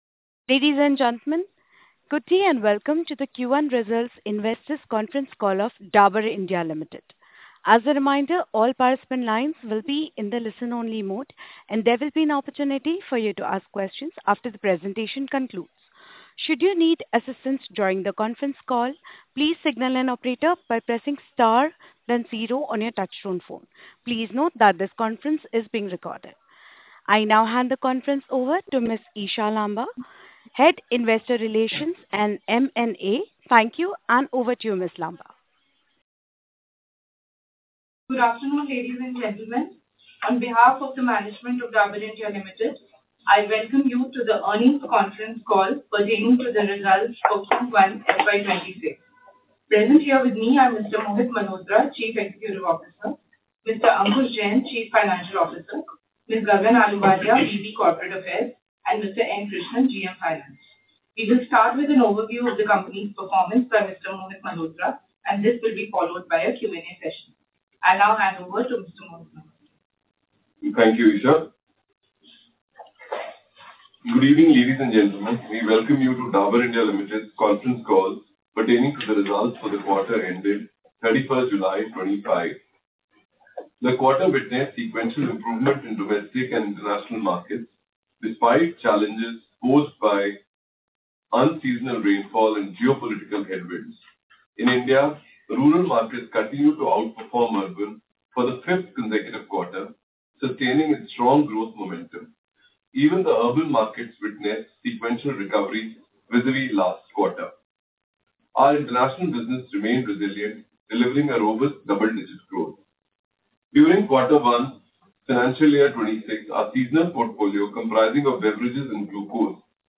Audio Recording for Investors' Conference Call.mp3